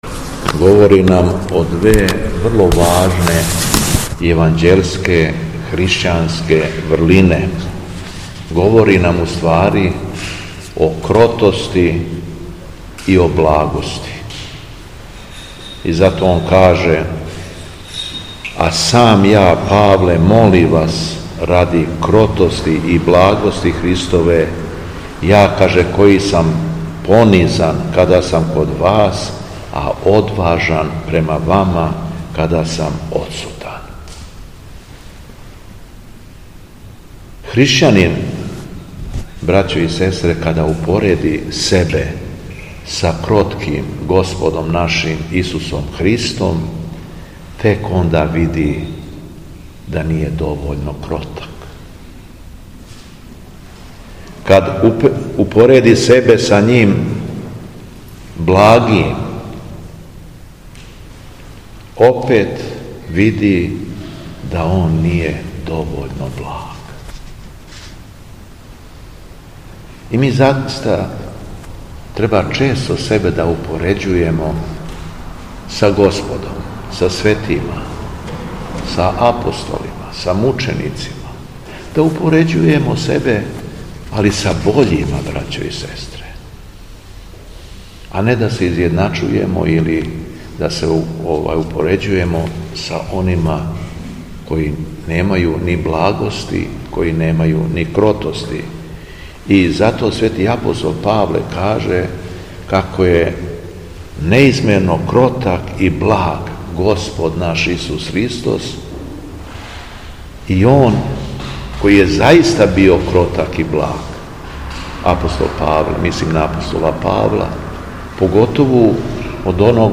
Беседа Његовог Високопреосвештенства Митрополита шумадијског г. Јована
Митрополит шумадијски се након прочитаног Јеванђеља обратио сабраном народу: